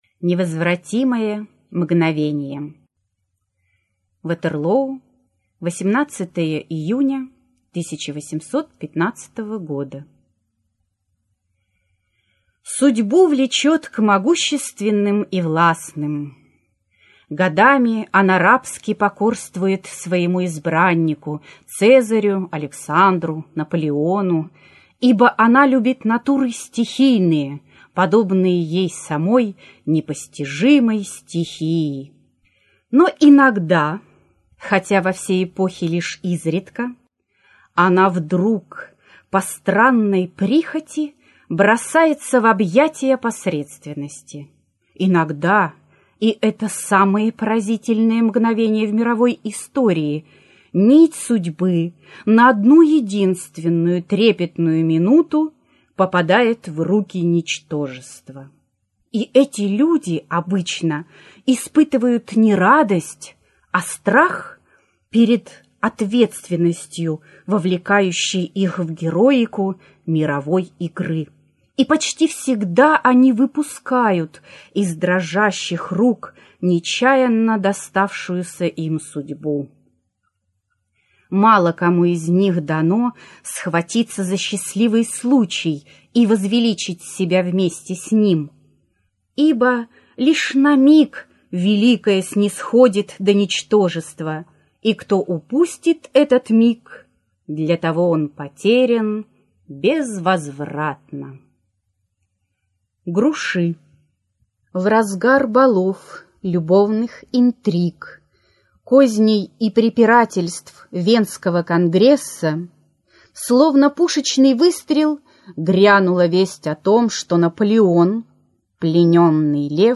Аудиокнига Невозвратимые мгновения. Новеллы | Библиотека аудиокниг